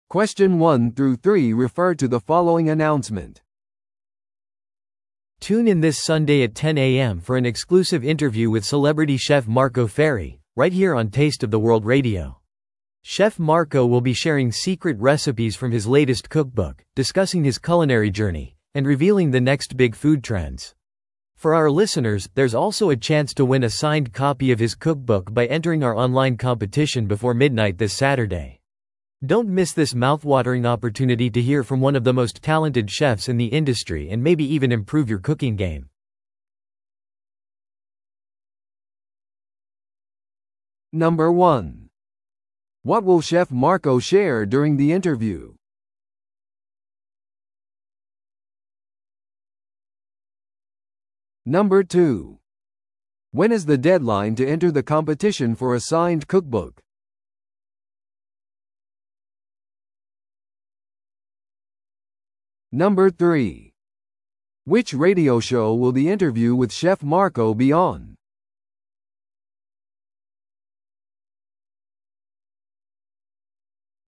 TOEICⓇ対策 Part 4｜有名シェフのインタビュー告知 – 音声付き No.119